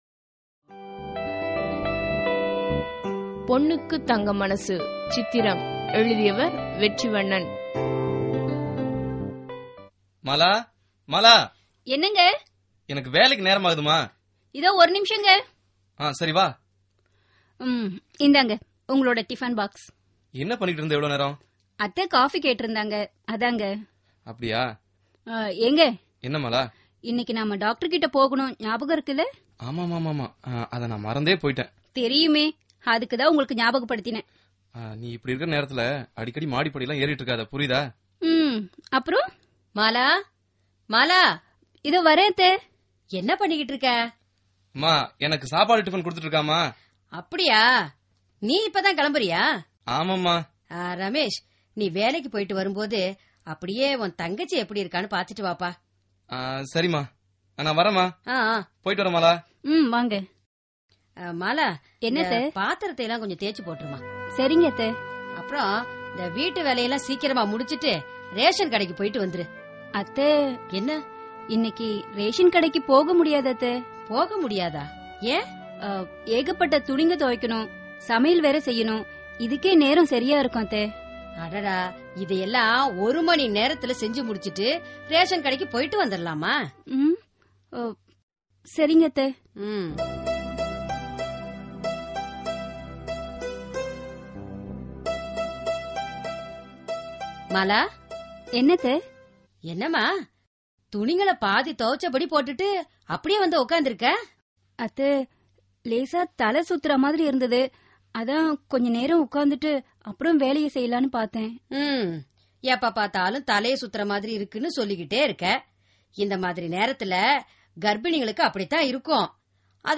Social Drama